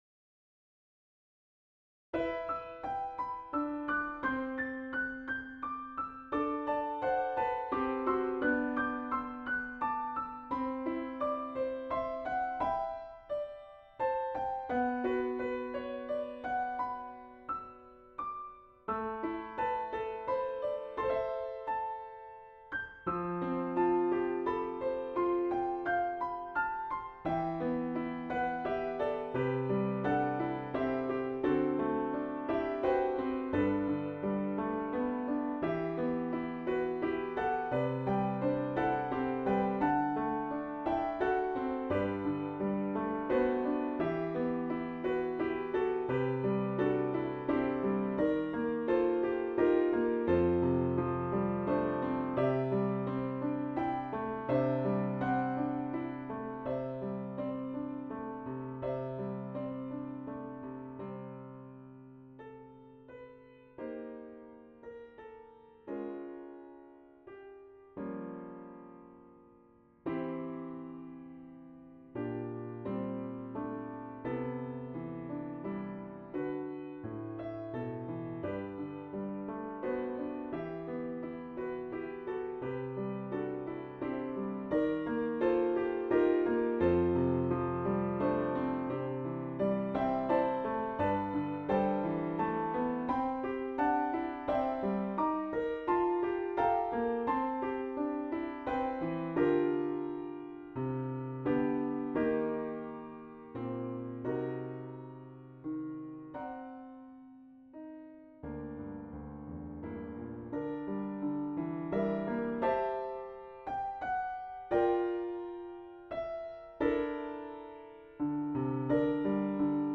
Above The Clouds (inspired by Final Fantasy "To Zanarkand") - Piano Music, Solo Keyboard - Young Composers Music Forum